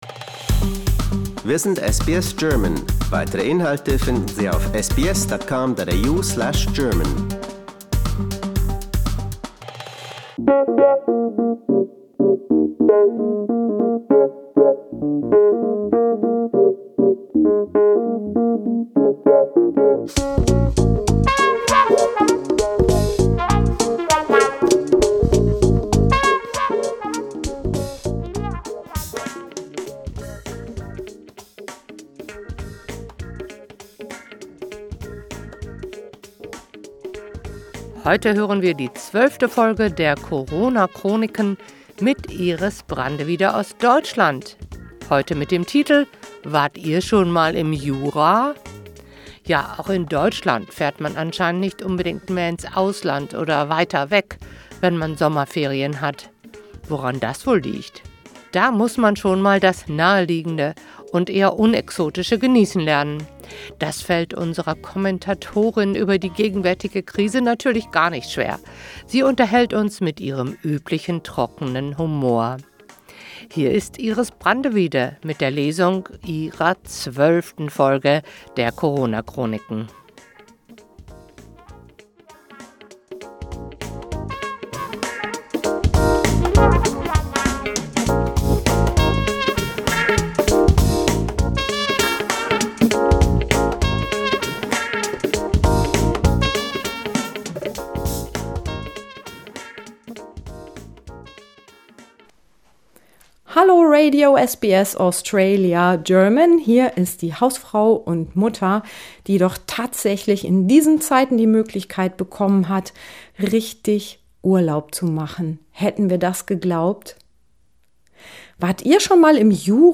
Sie unterhält uns mit ihrem üblichen, trockenem Humor.